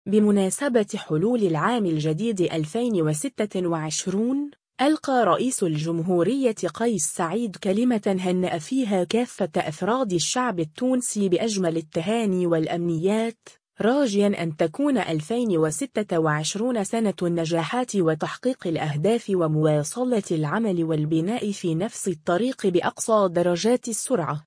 كلمة رئيس الجمهورية قيس سعيد إلى الشعب التونسي بمناسبة حلول السنة الإدارية الجديدة 2026 (فيديو)